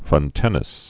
(frŭn-tĕnĭs, frŏntĕnĭs)